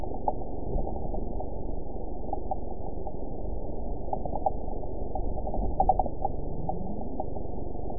event 912156 date 03/19/22 time 12:37:22 GMT (3 years, 1 month ago) score 9.21 location TSS-AB05 detected by nrw target species NRW annotations +NRW Spectrogram: Frequency (kHz) vs. Time (s) audio not available .wav